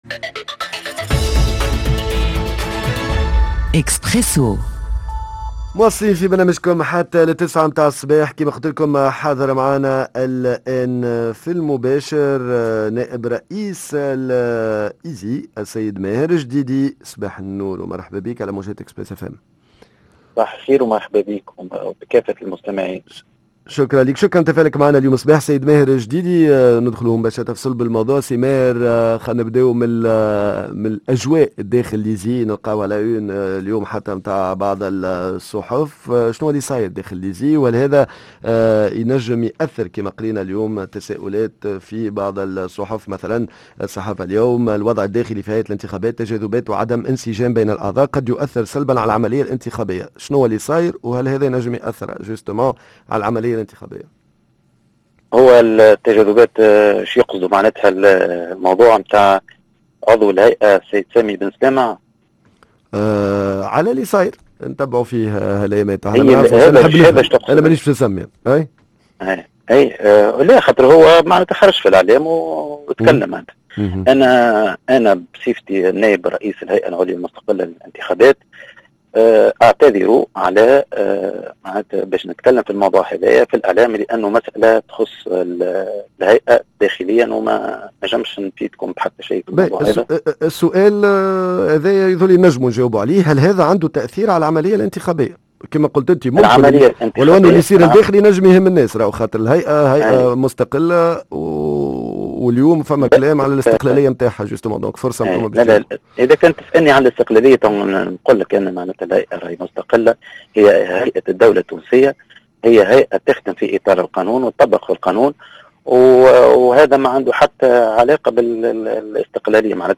شنوة العقوبات الي تسلطها هيئة الانتخابات على الاشخاص الي يستعملو شعار الجمهورية في المعلقات، قداش من مخالفة تم رفعها إلى حد الآن، ضيفنا نائب رئيس الهيئة ماهر الجديدي